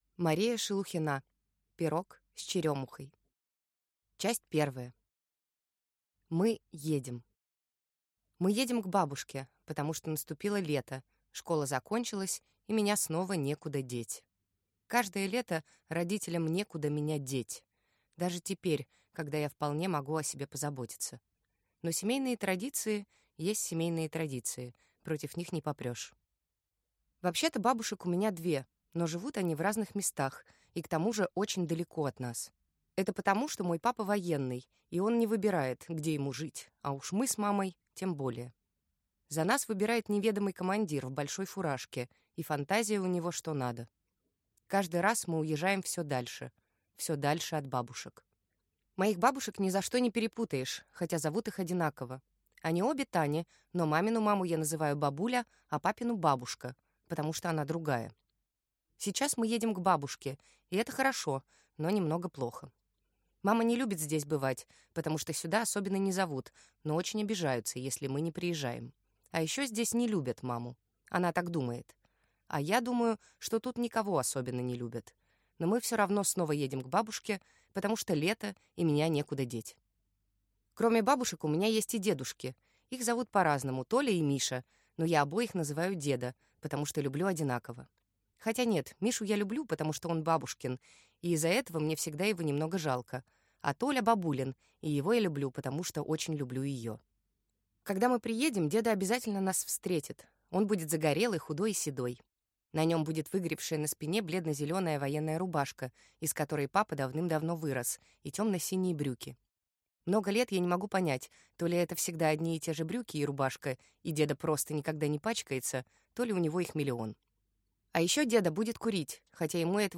Аудиокнига Пирог с черёмухой | Библиотека аудиокниг